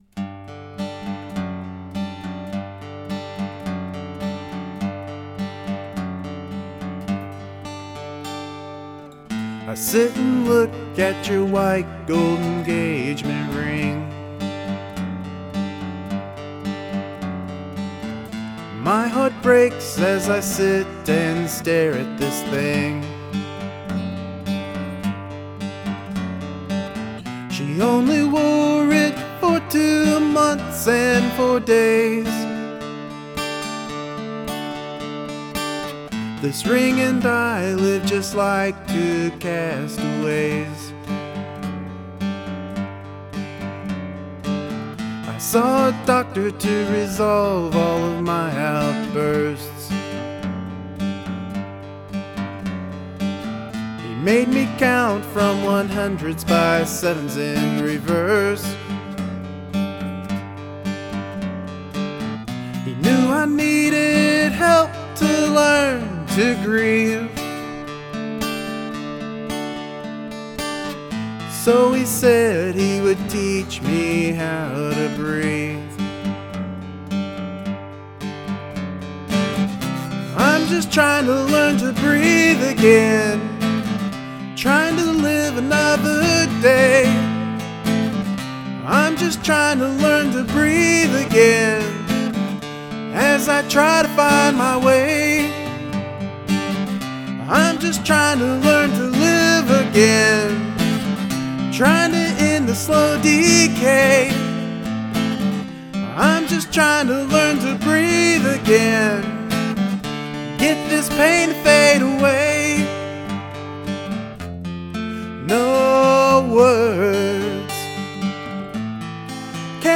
Good song with a very clean and simple sound. The dynamics in your voice and playing gives this a lot of weight.
Your voice and acoustic guitar is perfect combination!
The clean solo folk guitar is solid and i think the bare bones production is perfect for the song...just you and the ring....and your thoughts.
The guitar picking sequence is catchy and I love the chorus.